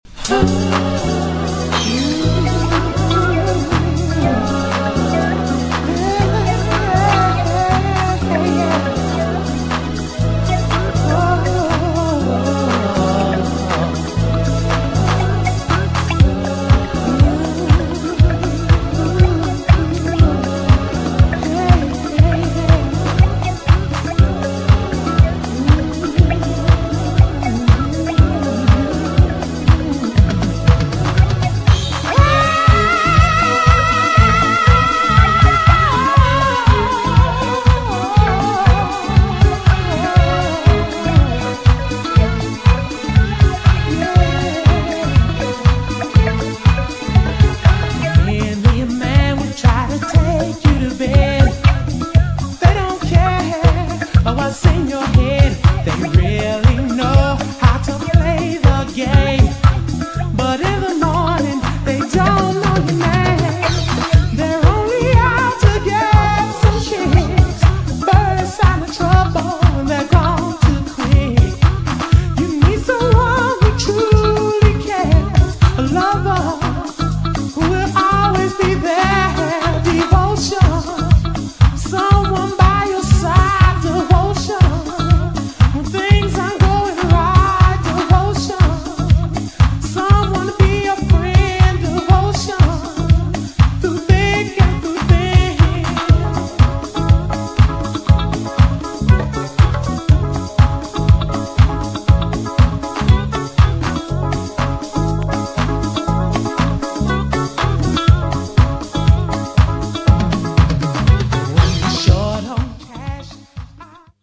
GENRE House
BPM 116〜120BPM